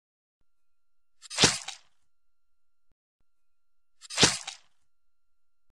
دانلود آهنگ چاقو زدن 1 از افکت صوتی اشیاء
جلوه های صوتی
دانلود صدای چاقو زدن 1 از ساعد نیوز با لینک مستقیم و کیفیت بالا